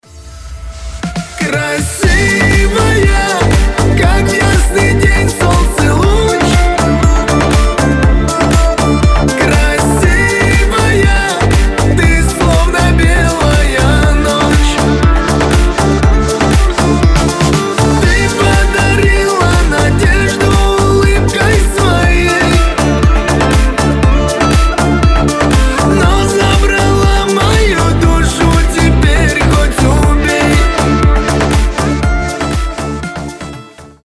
• Качество: 320, Stereo
красивые
мелодичные